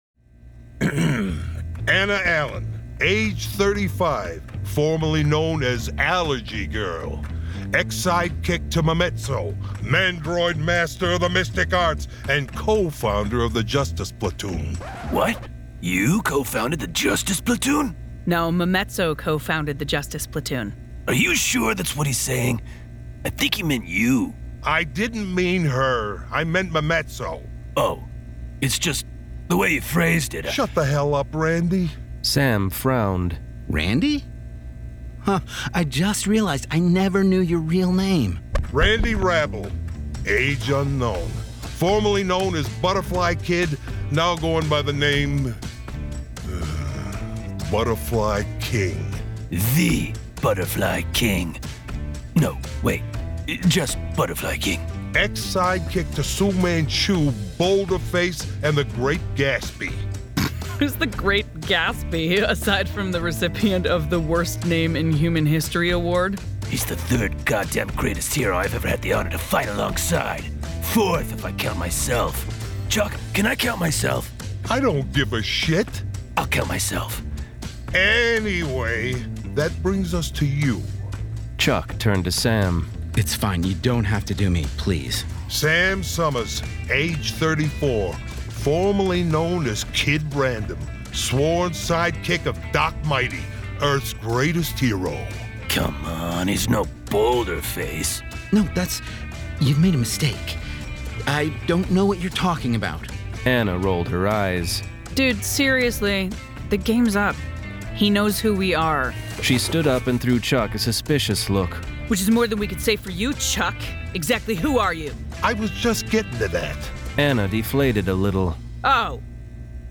Full Cast. Cinematic Music. Sound Effects.
[Dramatized Adaptation]
Genre: Humor